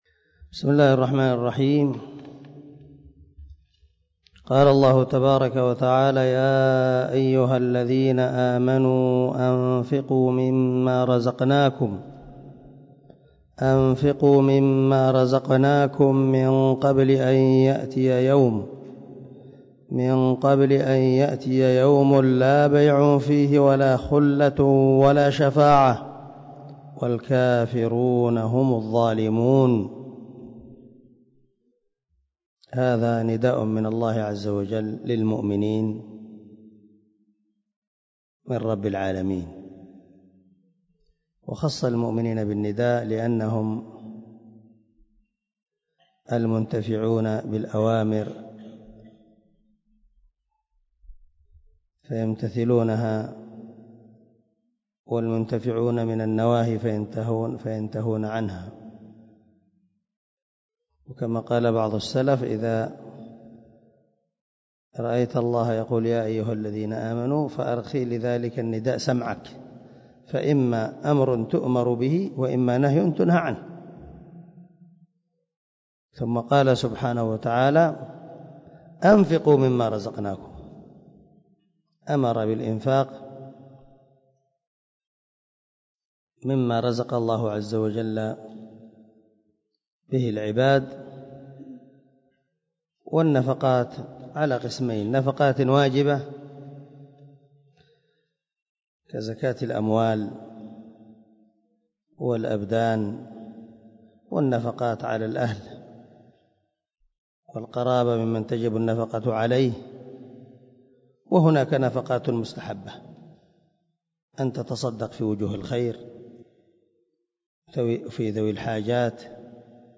132الدرس 122 تفسير آية ( 254 ) من سورة البقرة من تفسير القران الكريم مع قراءة لتفسير السعدي
دار الحديث- المَحاوِلة- الصبيحة.